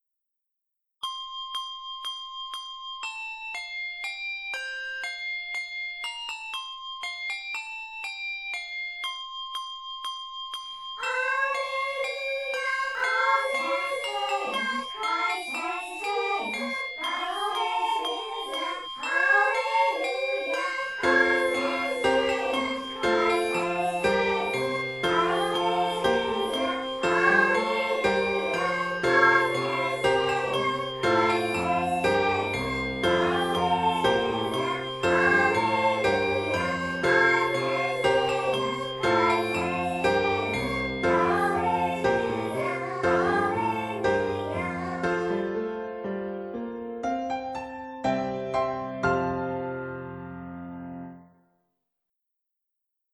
handbells, and keyboard.
for choir, handbells, and keyboard